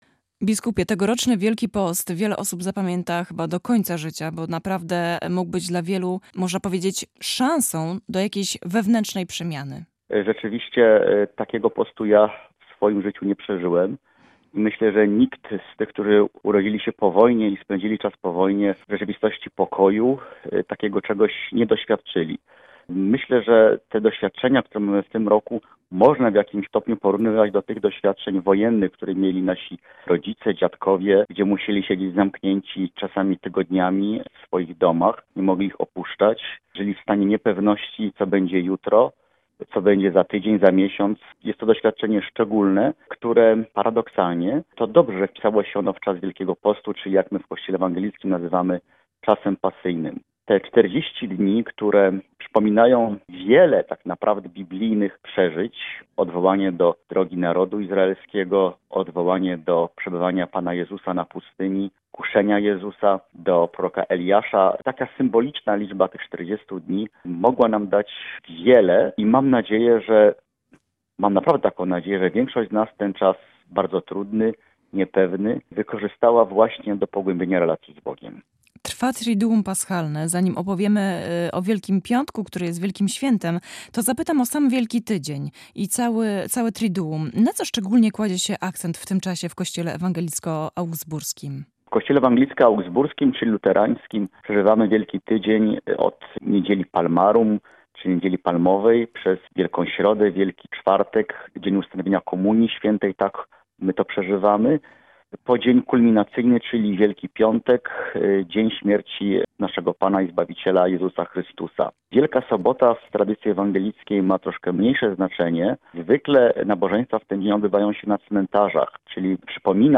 – Mijający Wielki Post oraz Triduum, które przeżywamy, jest wyjątkowe.